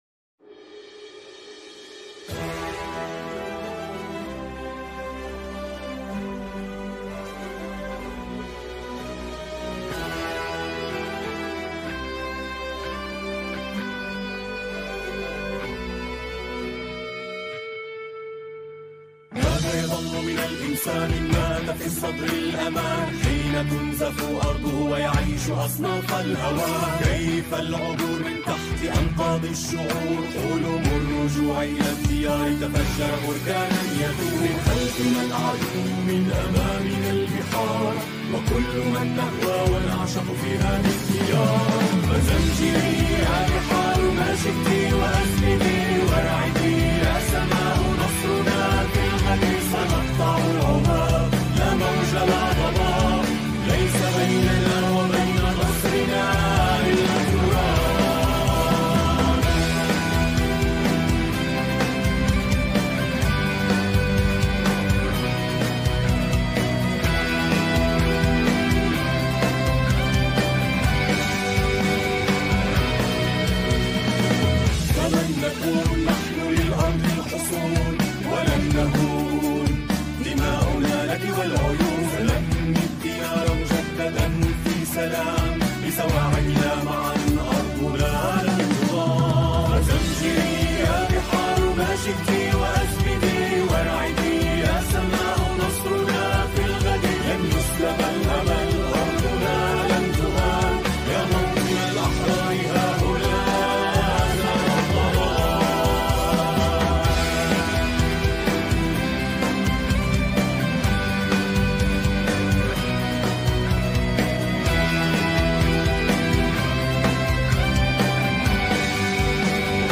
شارة البداية